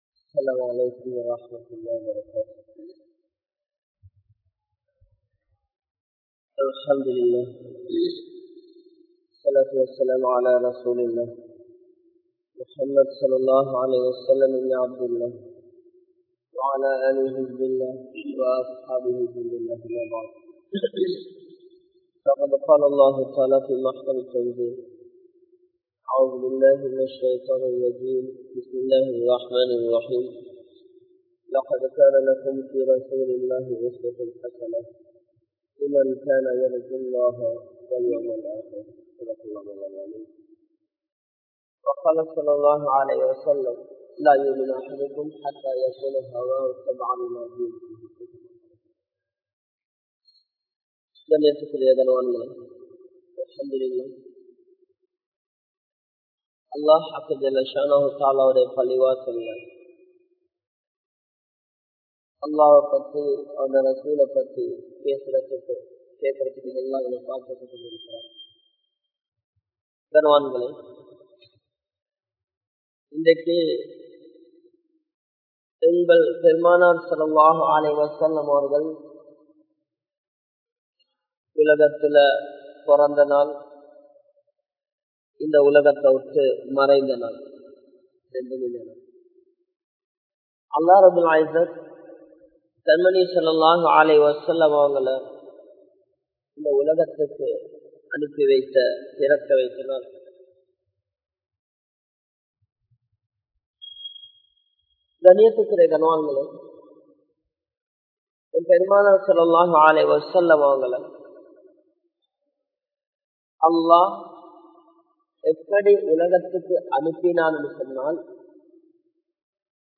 Atputham Niraintha Manithar (அற்புதம் நிறைந்த மனிதர்) | Audio Bayans | All Ceylon Muslim Youth Community | Addalaichenai